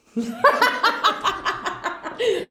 LAUGHTER.wav